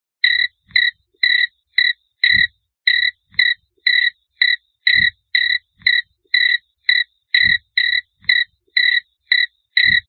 10 Secs pure Crickets